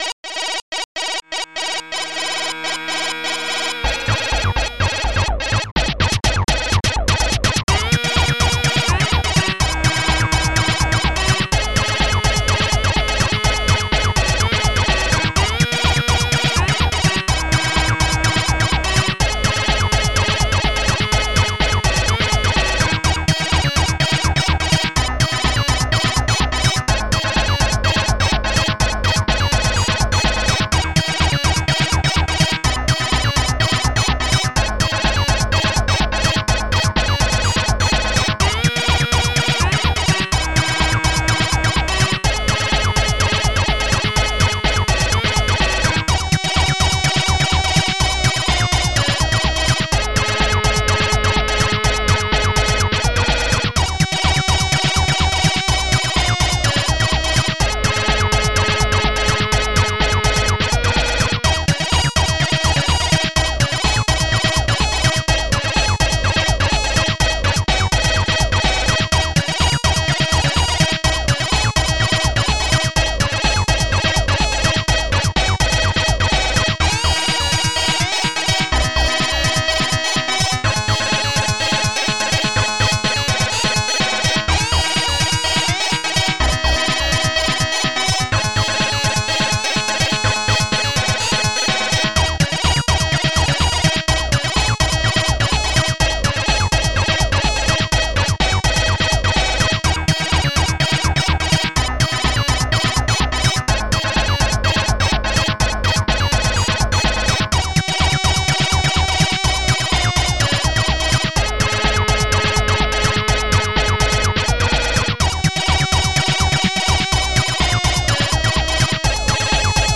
Protracker and family